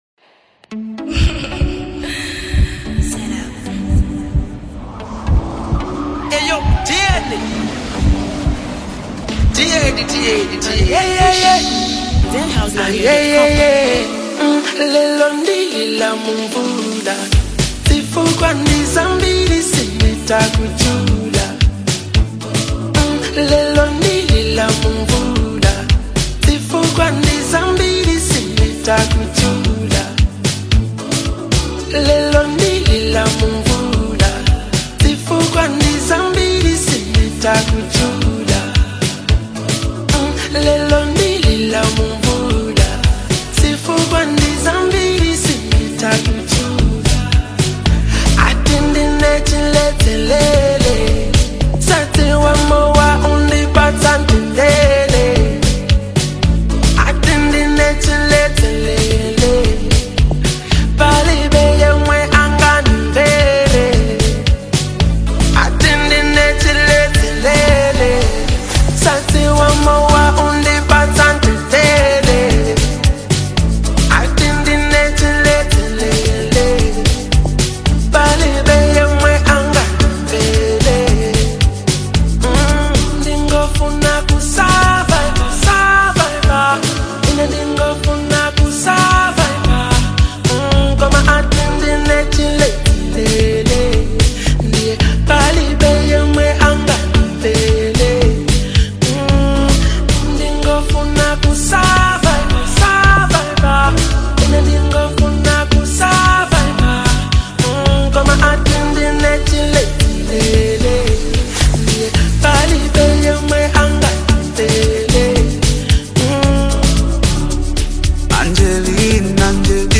his signature soulful touch